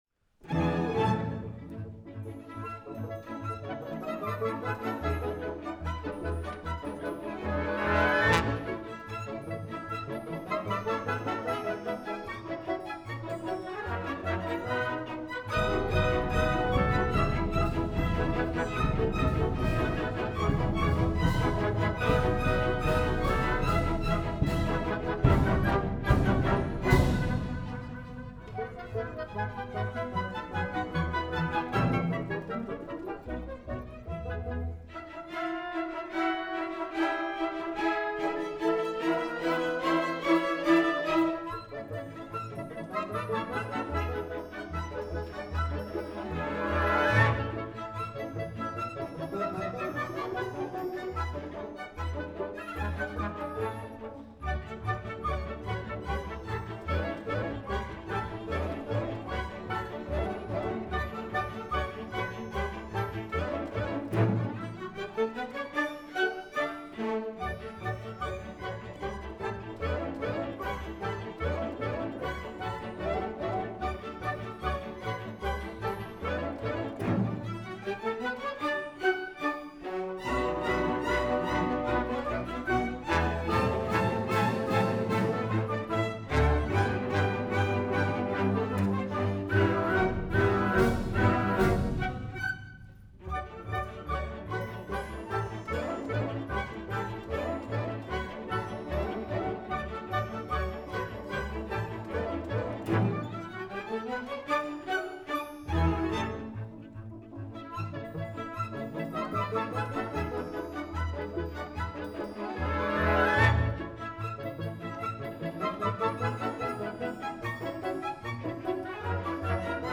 Concert du nouvel an 2026
(Wav stéréo 48Khz 24Bits non compressé, nécessite un haut-débit)